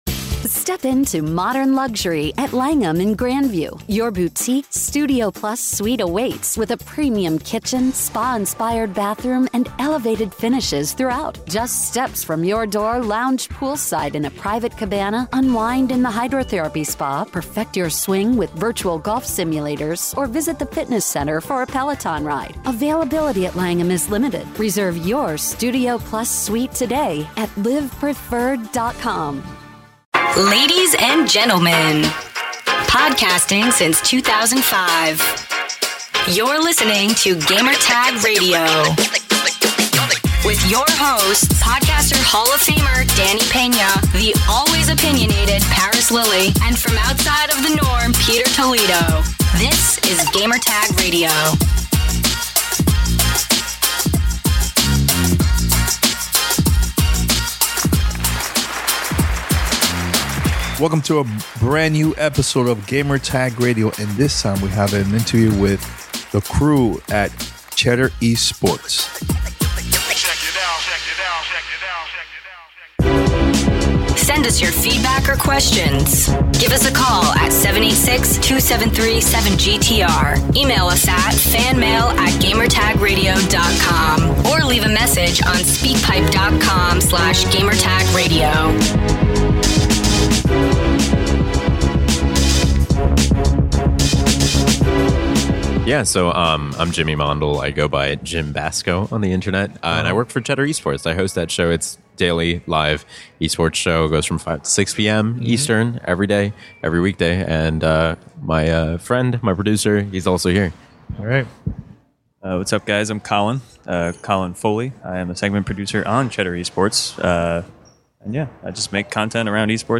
Cheddar Esports Interview